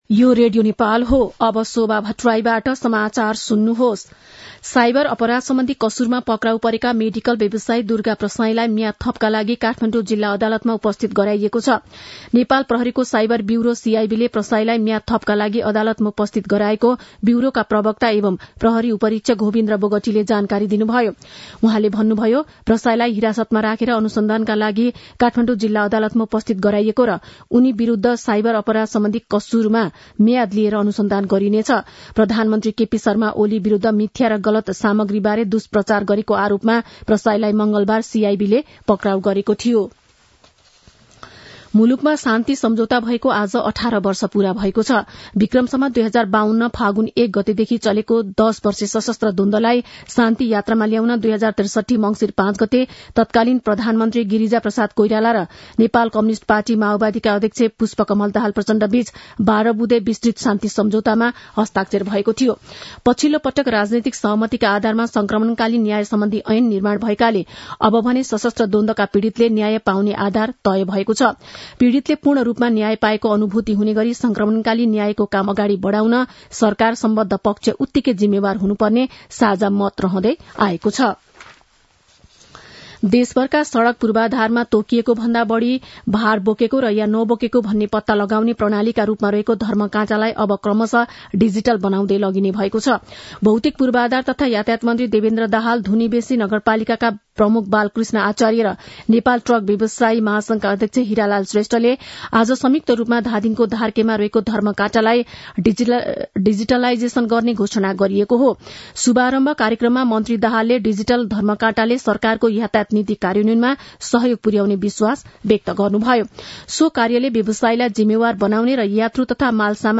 मध्यान्ह १२ बजेको नेपाली समाचार : ६ मंसिर , २०८१
12-am-nepali-news-1-4.mp3